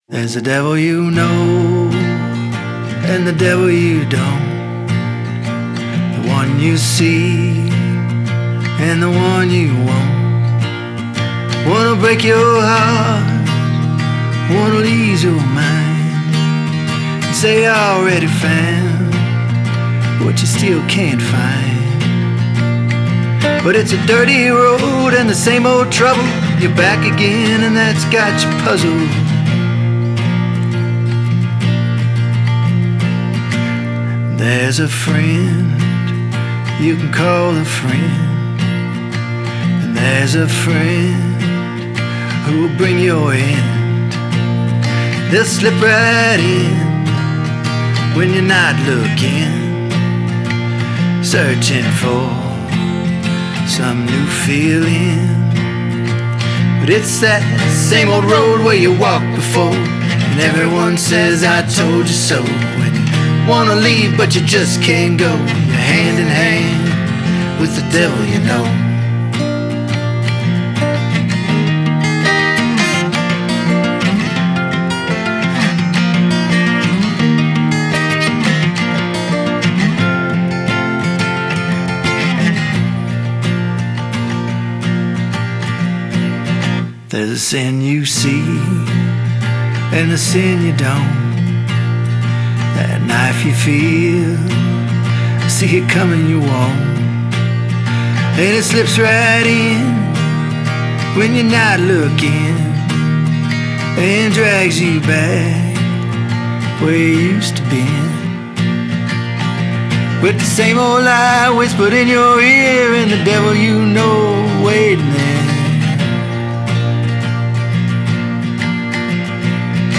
Com uma voz marcada pela experiência e pelo calor humano